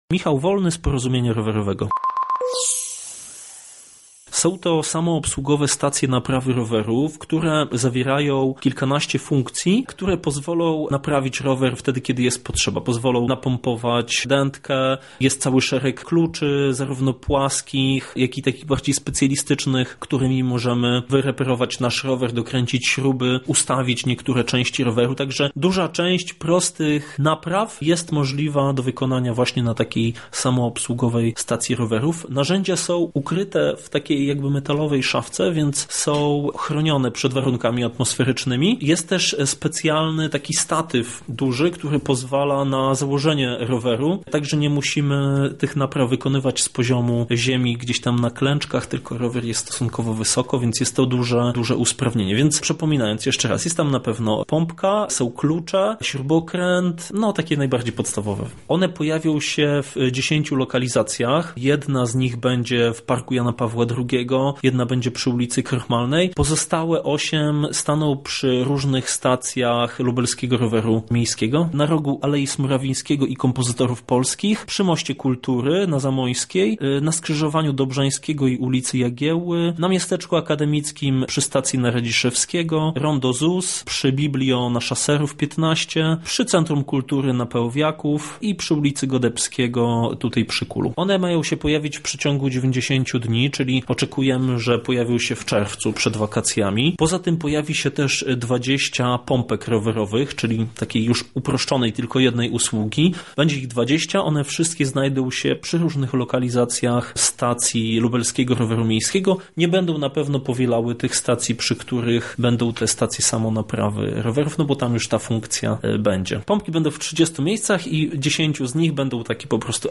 Gdzie staną punkty samoobsługowe, a także co dokładnie się w nich znajdzie – o tym w rozmowie naszej reporterki z ekspertem: